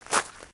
sounds / material / human / step / gravel01gr.ogg
gravel01gr.ogg